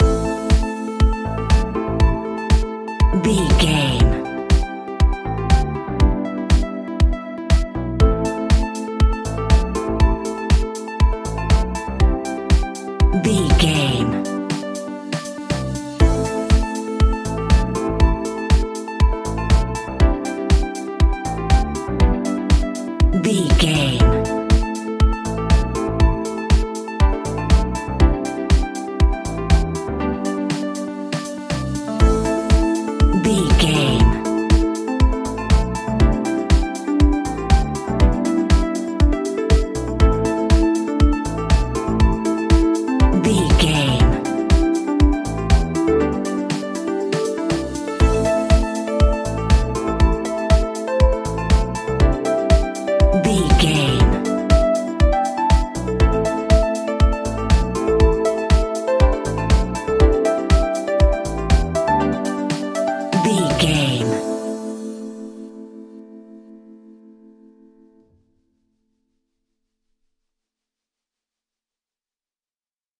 Aeolian/Minor
groovy
uplifting
driving
energetic
electric piano
bass guitar
synthesiser
drums
funky house
electro
upbeat
instrumentals